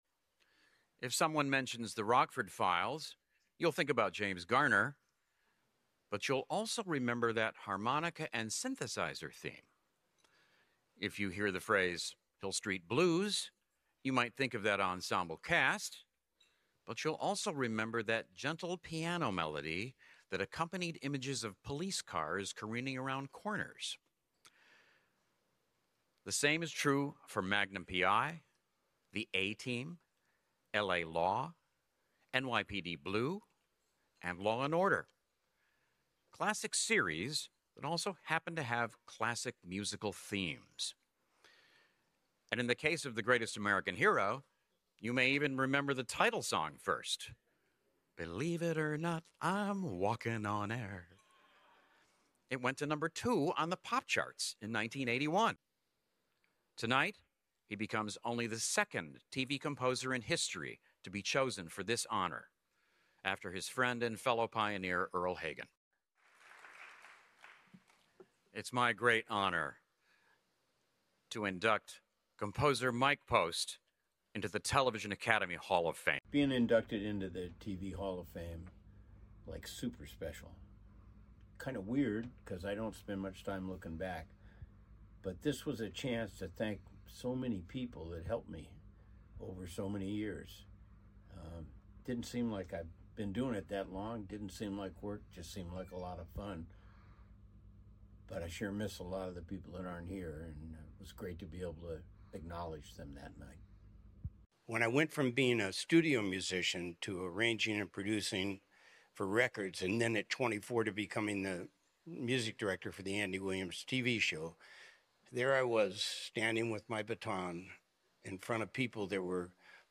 🎞 27th Television Academy Hall of Fame clip courtesy of the Television Academy / © Television Academy.